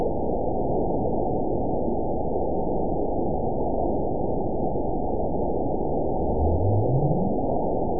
event 922385 date 12/30/24 time 05:02:55 GMT (11 months ago) score 9.62 location TSS-AB02 detected by nrw target species NRW annotations +NRW Spectrogram: Frequency (kHz) vs. Time (s) audio not available .wav